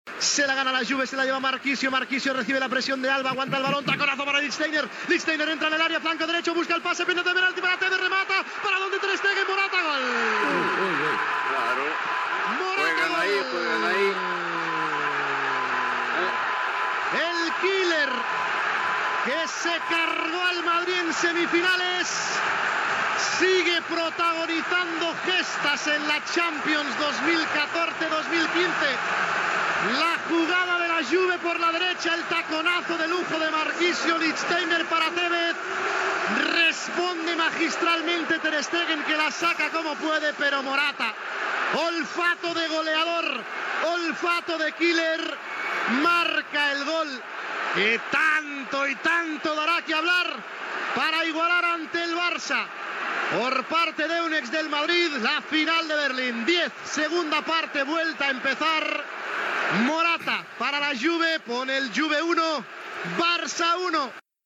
Transmissió de la final de la Copa d'Europa de futbol masculí, des de l'Olympiastadion de Berlín, del partit entre el Futbol Club Barcelona i la Juventus.
Esportiu